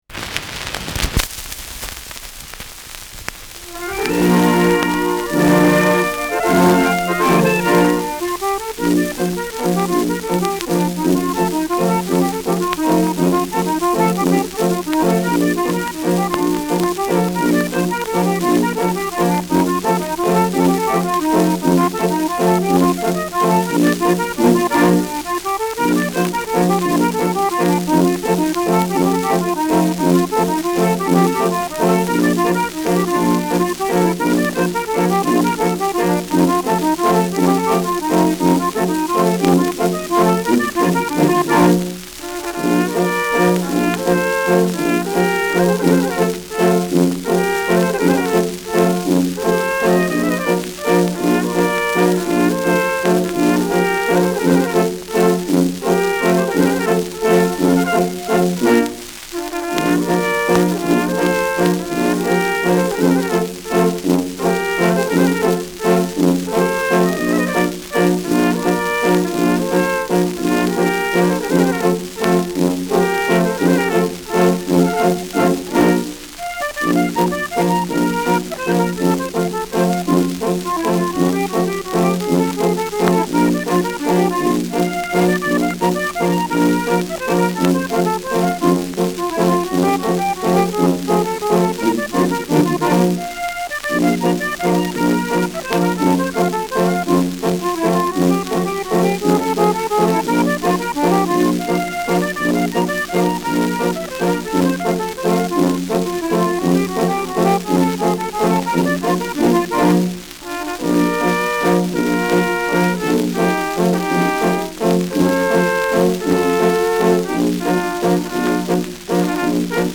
Schellackplatte
Abgespielt : Nadelgeräusch : Vereinzelt leichtes Knacken
Kapelle Jais (Interpretation)
[München] (Aufnahmeort)